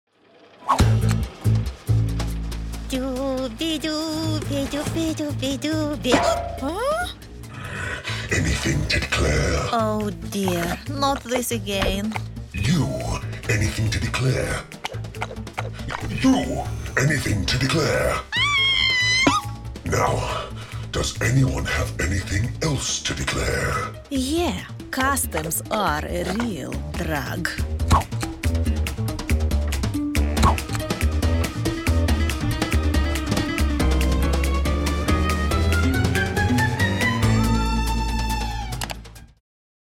Female
Assured, Authoritative, Bright, Bubbly, Character, Cheeky, Children, Confident, Cool, Corporate, Deep, Engaging, Friendly, Gravitas, Natural, Posh, Reassuring, Sarcastic, Smooth, Soft, Streetwise, Wacky, Warm, Witty, Versatile, Young, Approachable, Conversational, Energetic, Funny, Upbeat
Voice reels
Microphone: X1
Audio equipment: 2i2 interface, prof insulated studio (Skype, Zoom)